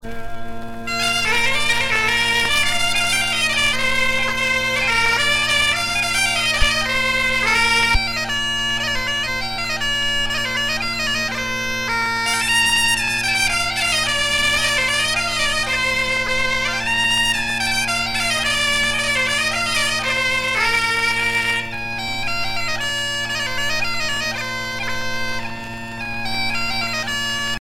danse : an dro